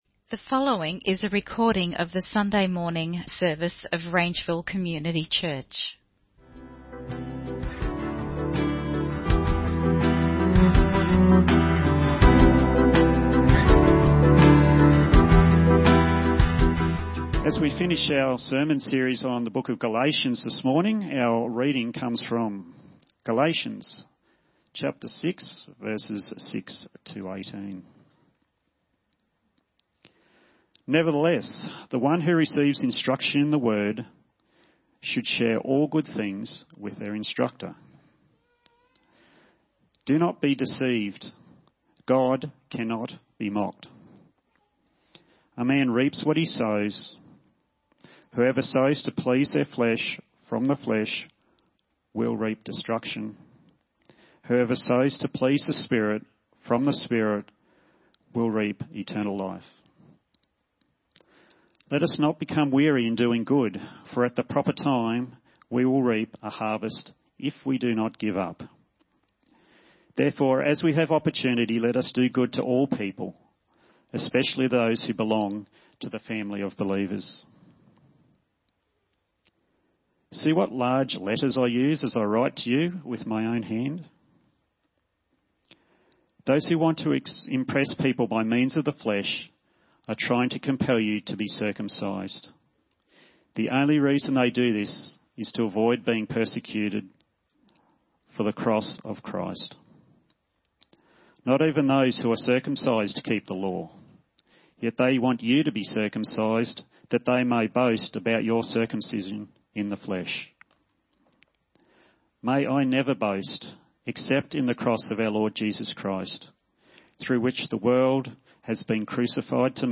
What Are You Sowing & Reaping? (Sermon Only - Video + Audio)